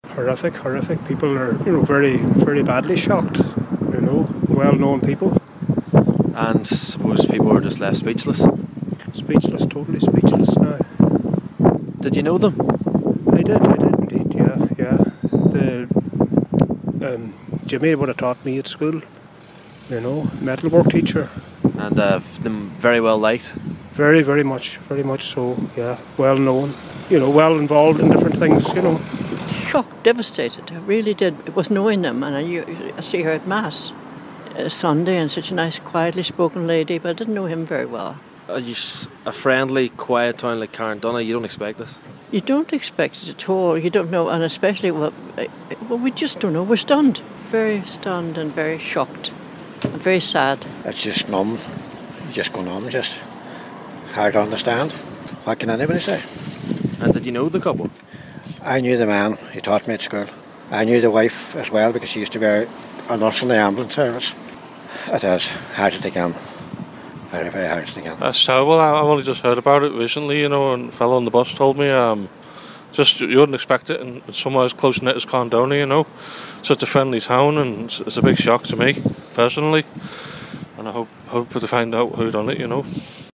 Audio: Carndonagh locals express shock at death of popular local couple